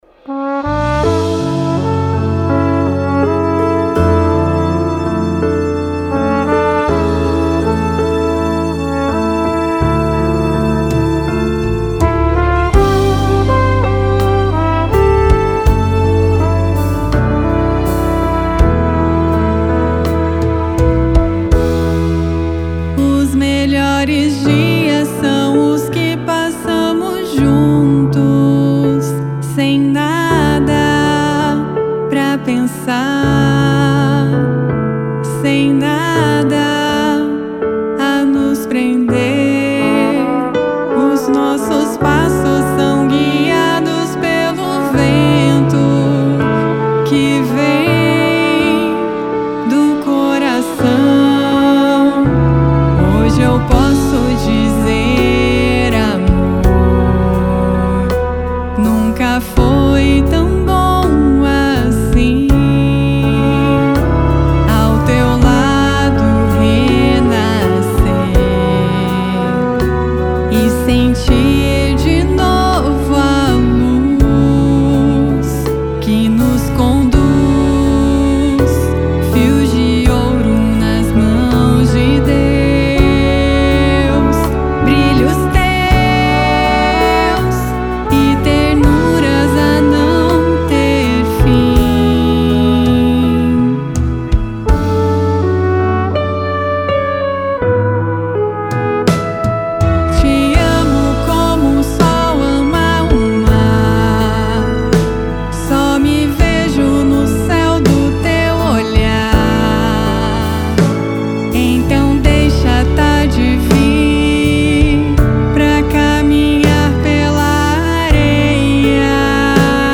Piano
• Cordas
• Flugelhorn
• Bateria